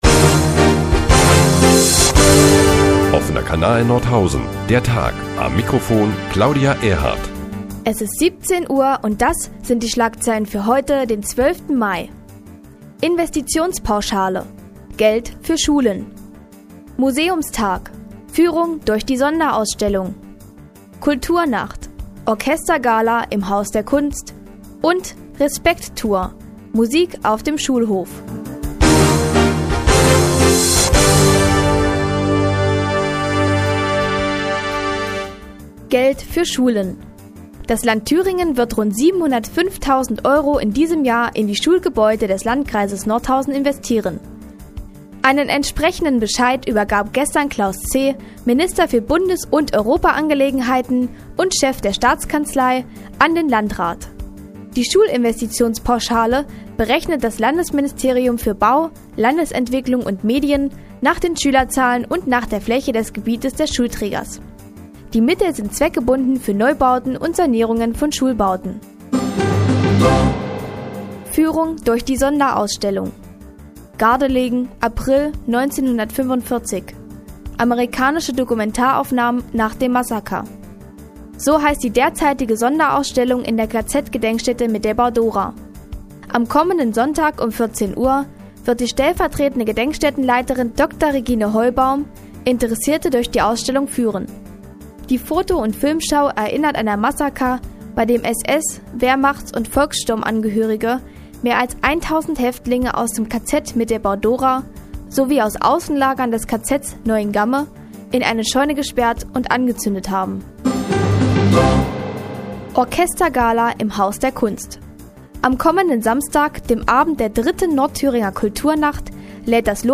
Die tägliche Nachrichtensendung des OKN ist nun auch in der nnz zu hören. Heute unter anderem mit einer Führung durch die Sonderausstellung der KZ- Gedenkstätte Mittelbau- Dora und der Verteilung von Respekt!-CD's auf dem Schulhof.